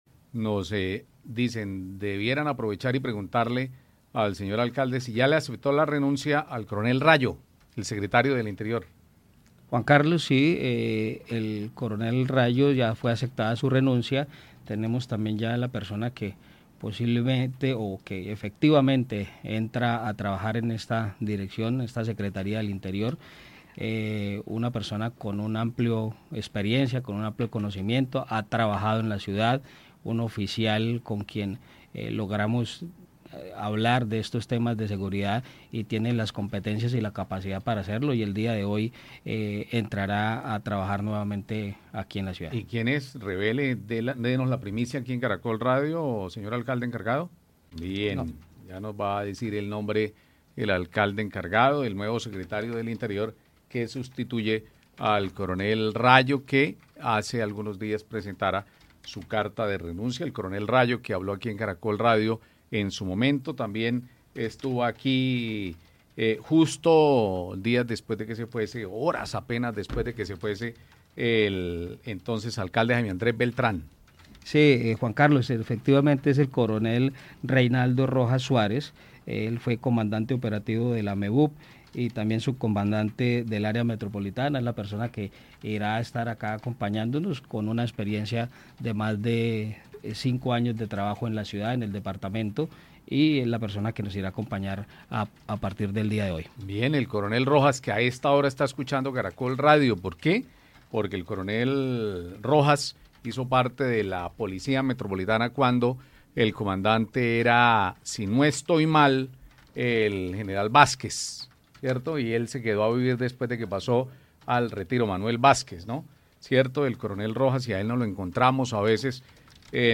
Eduard Sánchez, alcalde (e) de Bucaramanga